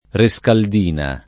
Rescaldina [ re S kald & na ]